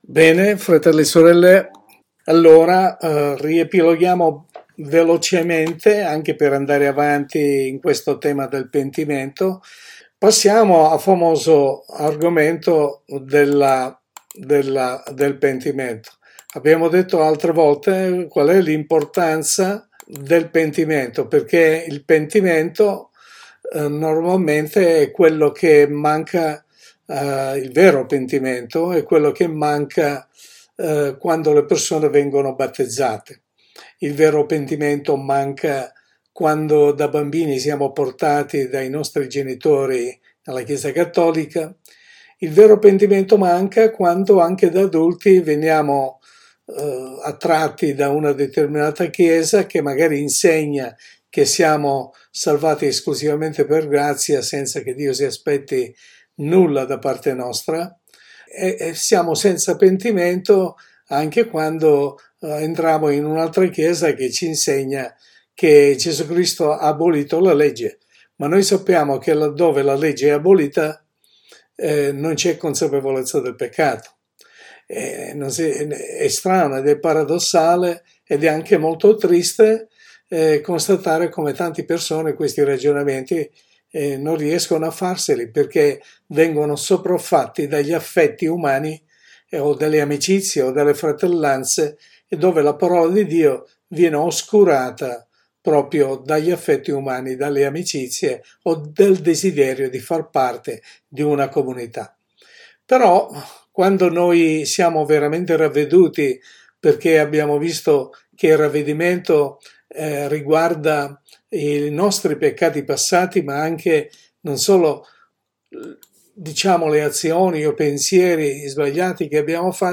Studio Biblico